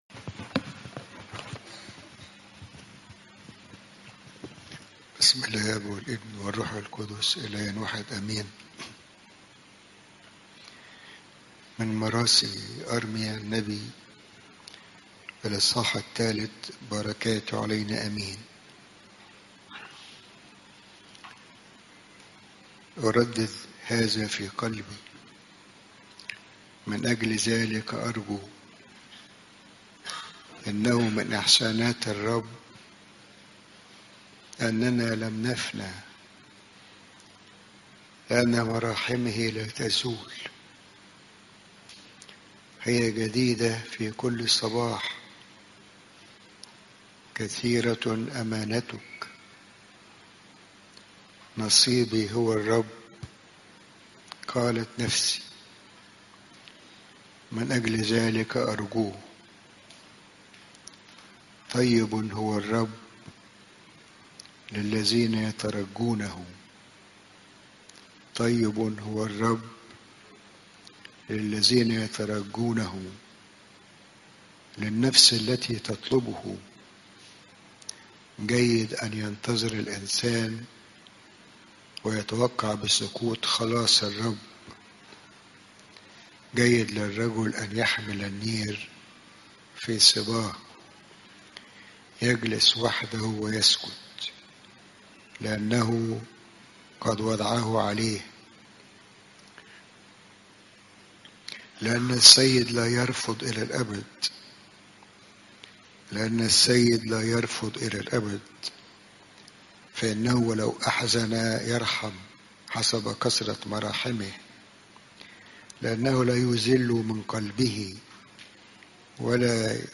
إجتماع